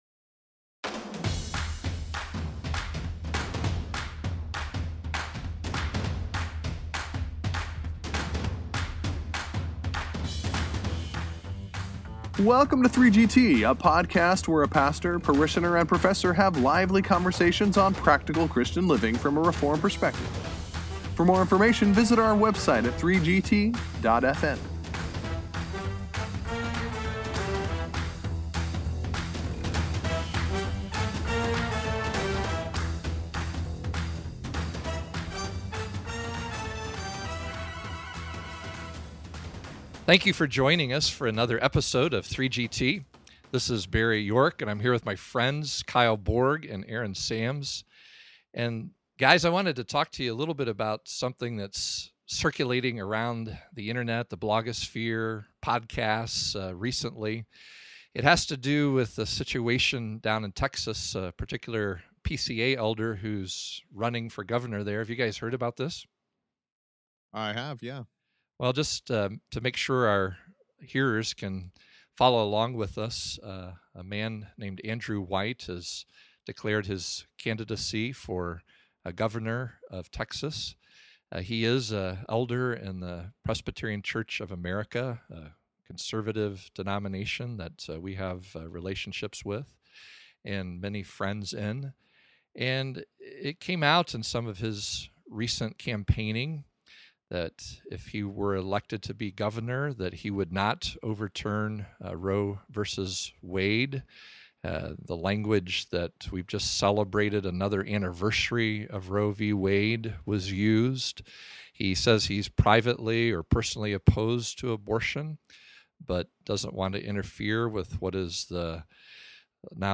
Join them for this wide-ranging “trialogue” on 3GT!